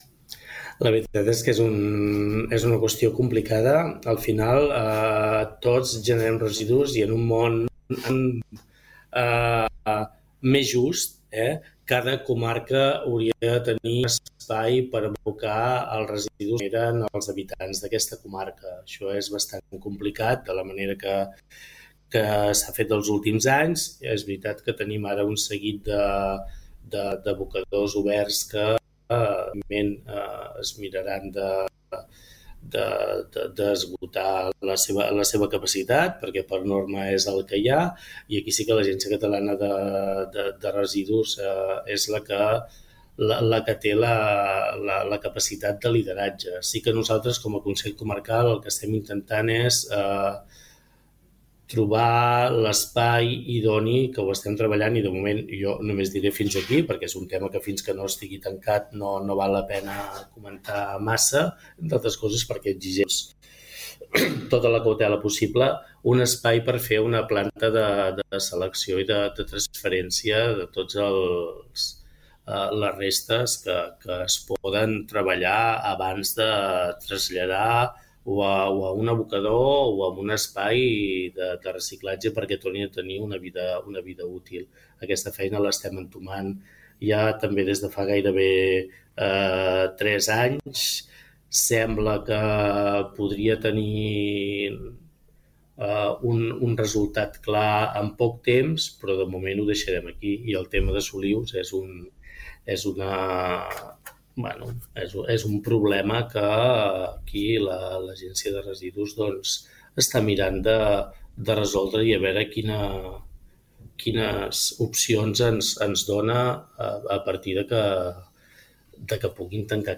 En una entrevista a Ràdio Capital, ha detallat els reptes principals del seu mandat: impulsar l’economia blava, consolidar un hub cultural comarcal, millorar la mobilitat i afrontar la gestió dels residus a la comarca.